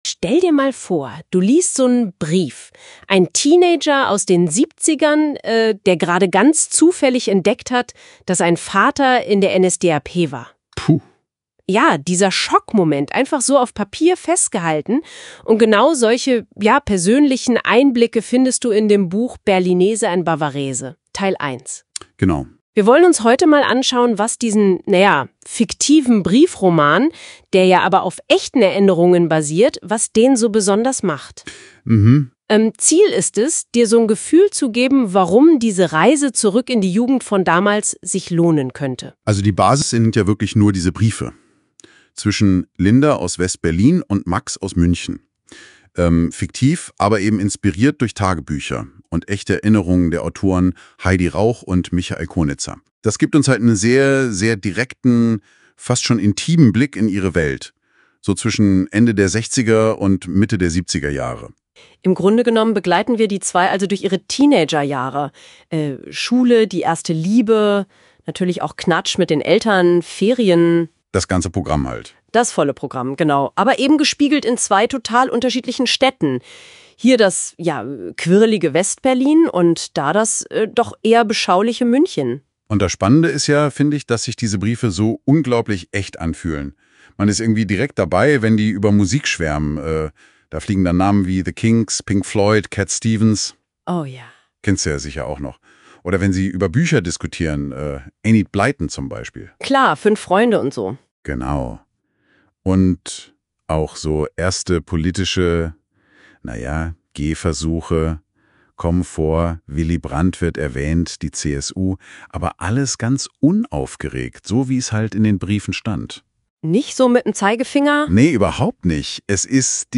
podcastkiinterview.m4a